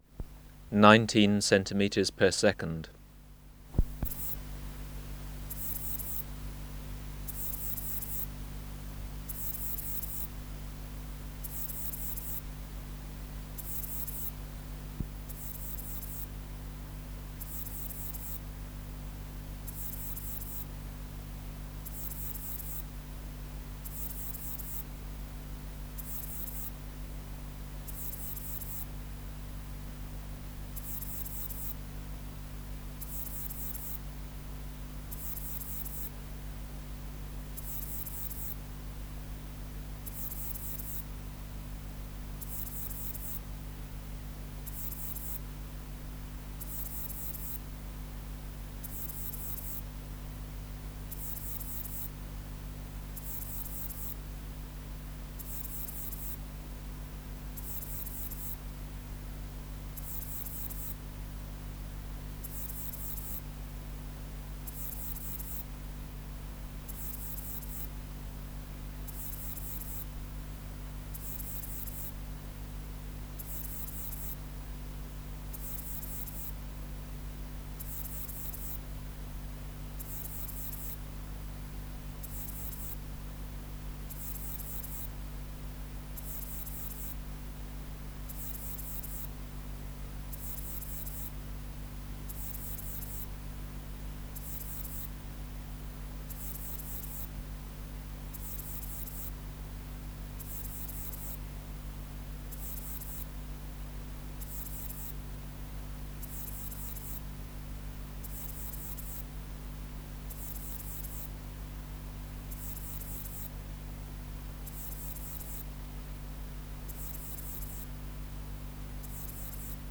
Natural History Museum Sound Archive Species: Ephippiger discoidalis
Recording Location: BMNH Acoustic Laboratory
Reference Signal: 1 kHz for 10s
Substrate/Cage: Large recording cage
Microphone & Power Supply: Sennheiser MKH 405 Distance from Subject (cm): 35 Filter: Low pass, 24 dB per octave, corner frequency 20 kHz